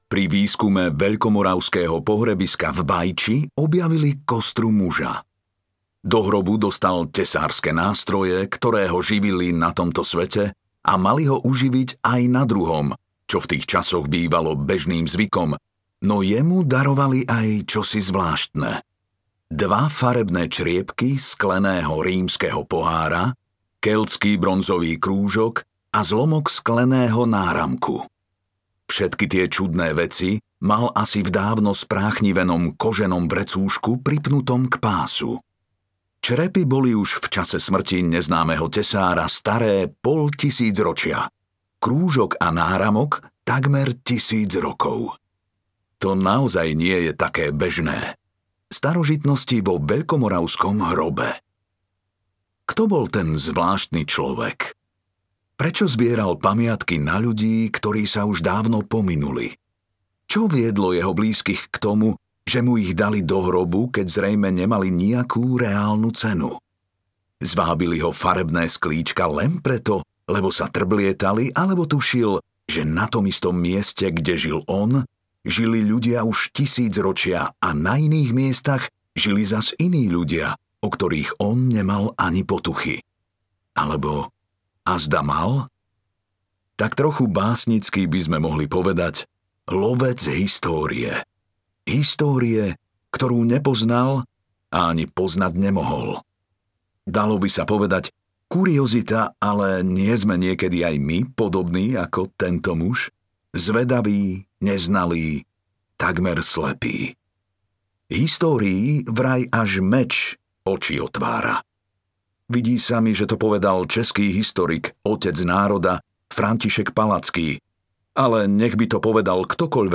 Stopy dávnej minulosti, Zrod národa audiokniha
Ukázka z knihy